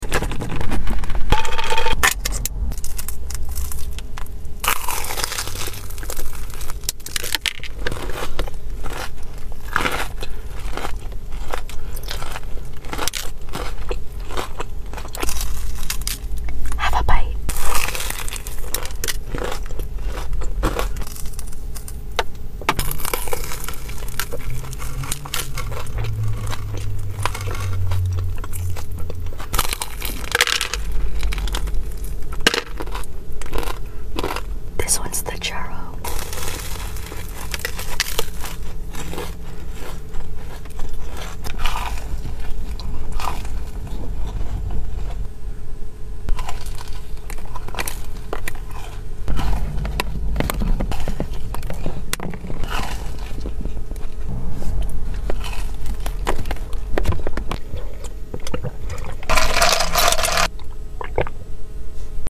eating korean corndogs sound effects free download